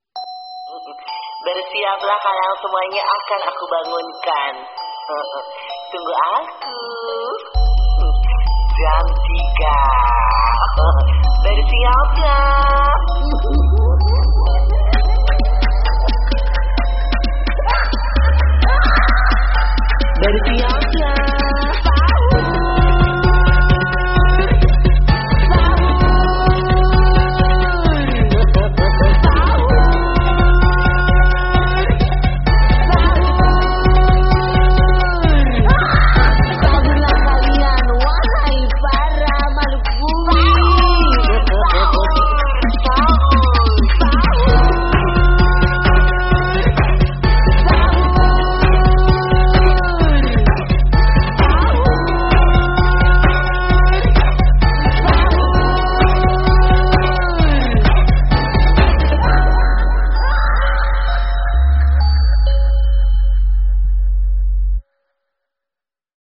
DJ Remix
Kategori: Nada dering